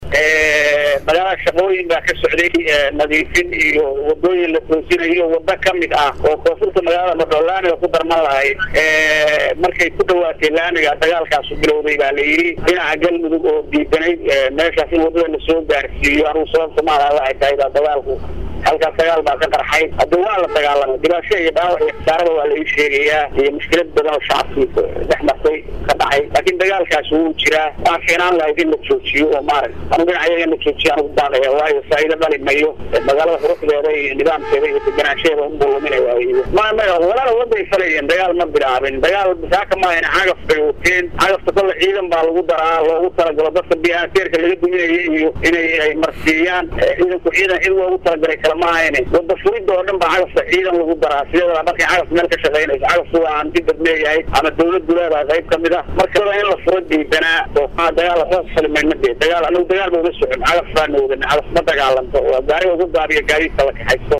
Codka Gudoomiyaga Gal-mudug